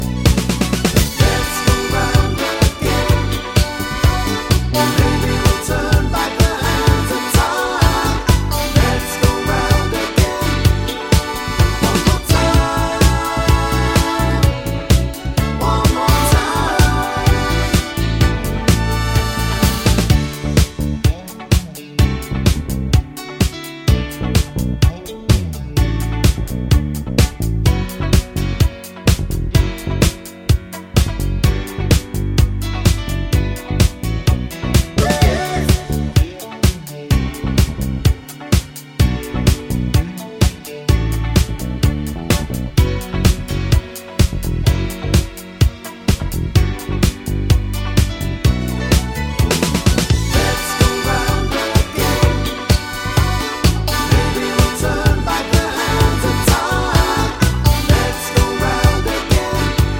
no Backing Vocals Disco 3:56 Buy £1.50